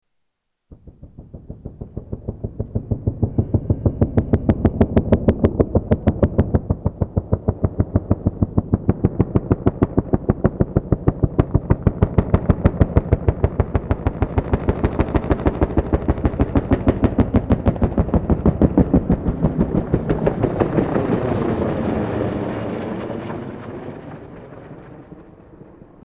Helicopter thwops